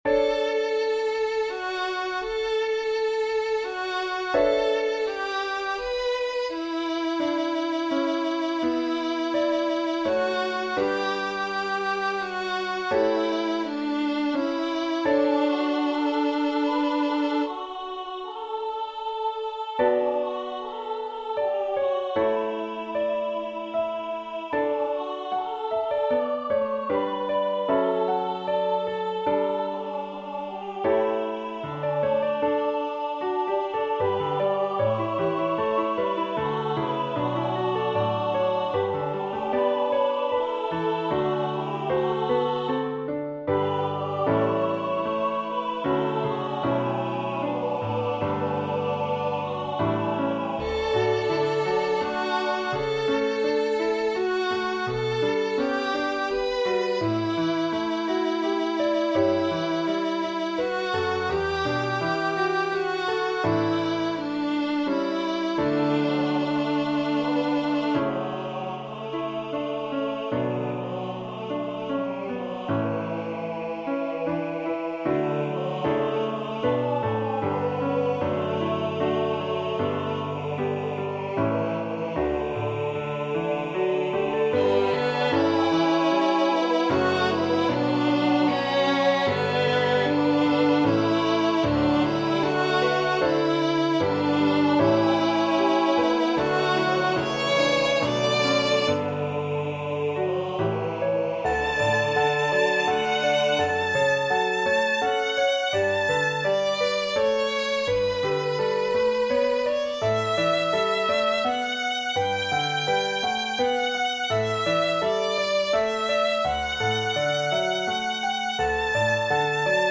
This is an SATB Arrangement with piano and violin.